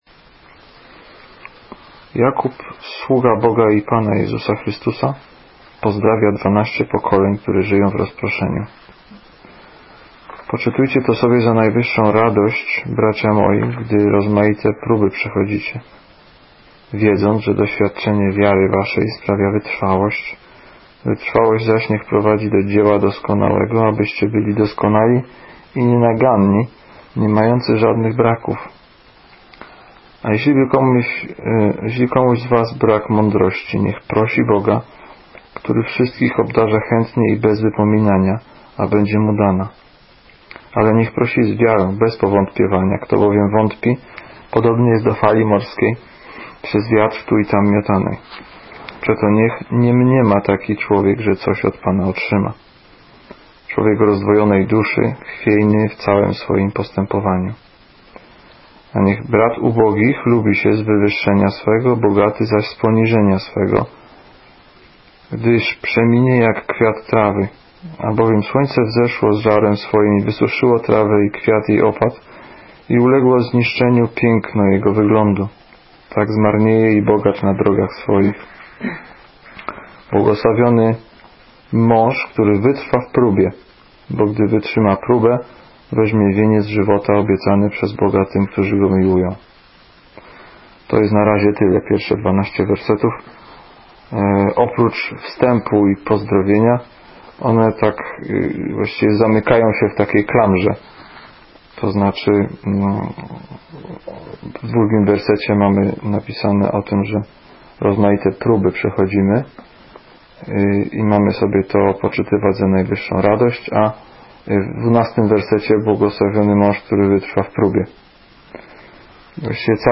Ulica Prosta - Kazania z 2008, cz.1